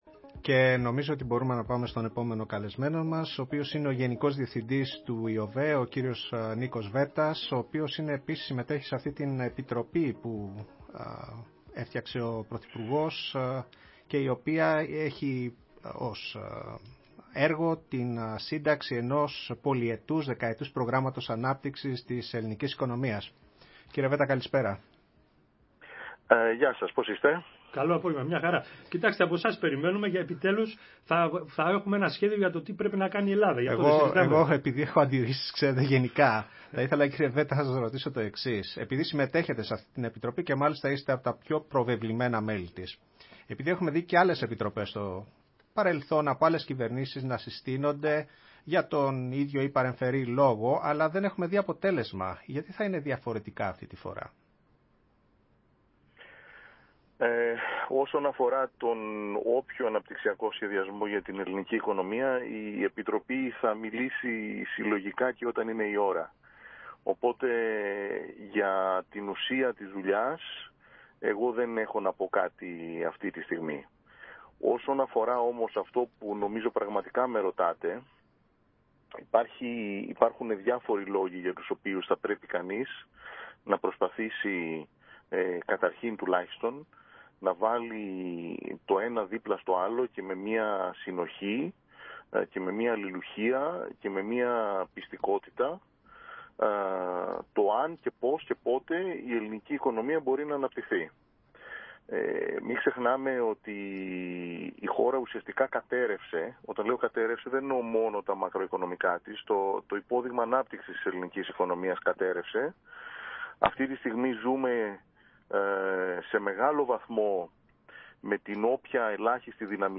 Συνέντευξη στο ραδιόφωνο της ΕΡΤ